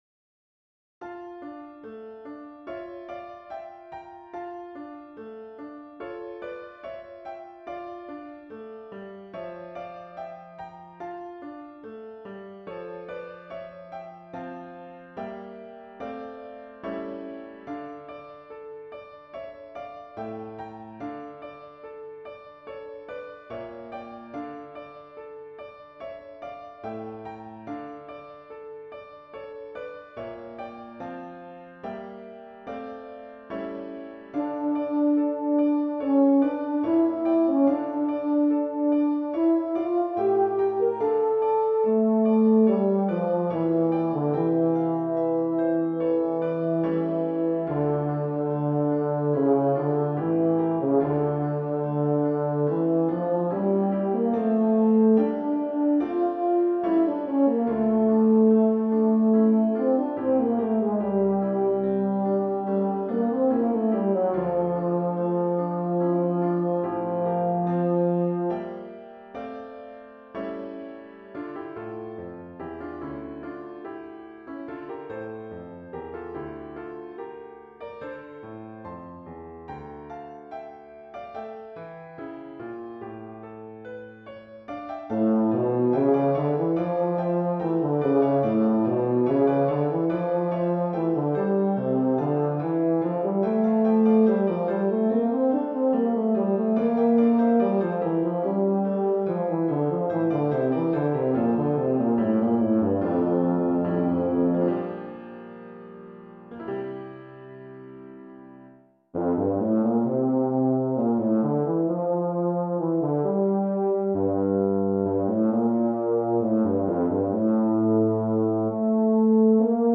Voicing: Euphonium Solo